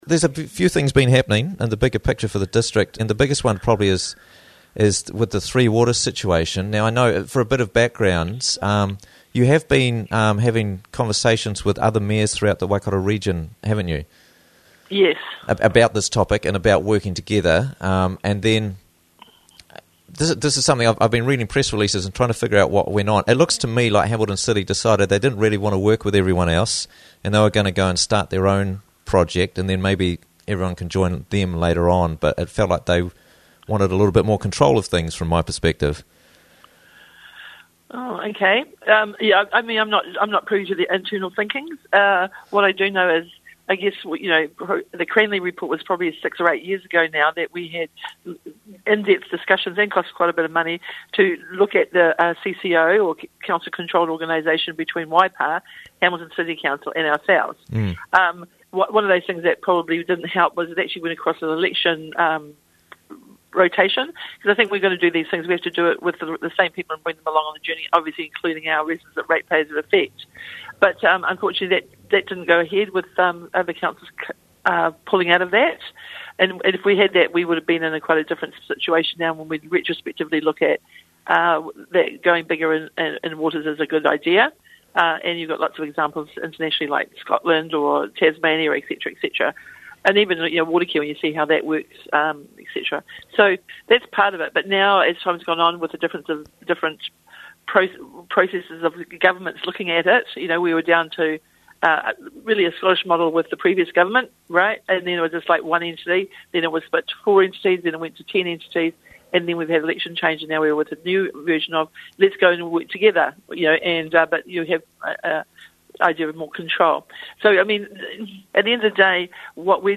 Mayor Jacqui Church - Interviews from the Raglan Morning Show